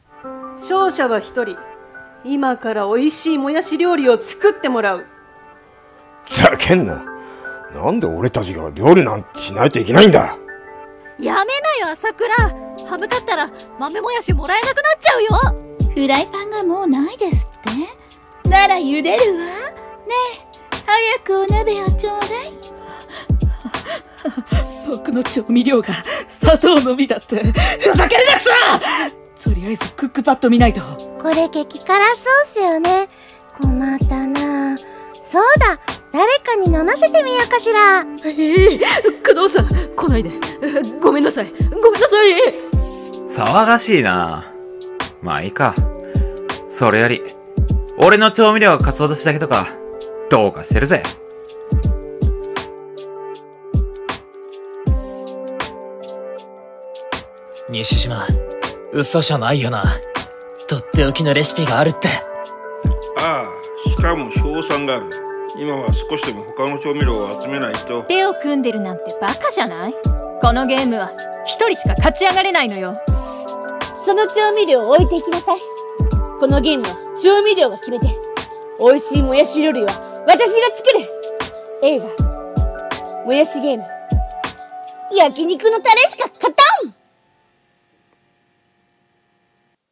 声劇【モヤシ・ゲーム】※14人声劇